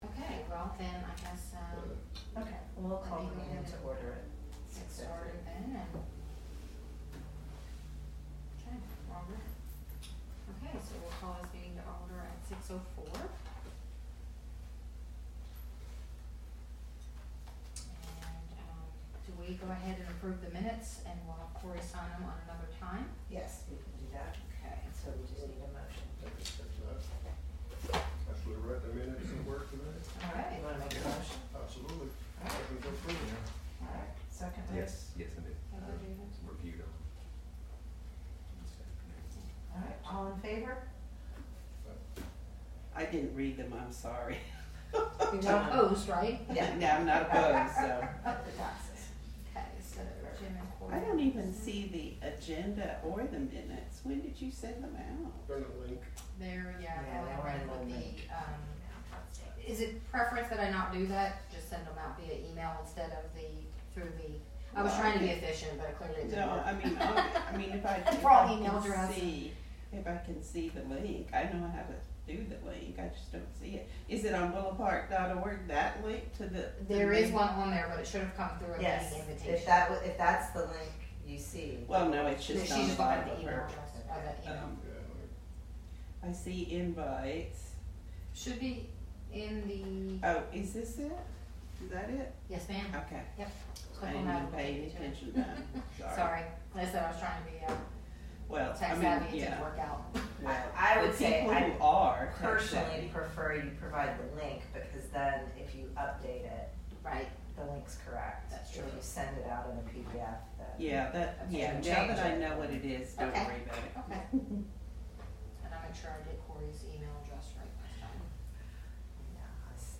The Willow Park Parks Board Meeting on Tuesday, 07 February 2023 will include presentations and discussions on the Willow Park Parks & Trails Master Plan; Cross Timbers Playground; future park and trail improvements with 5-year Capital Improvement Plan (CIP); The meeting is at 6p at the El Chico City Hall.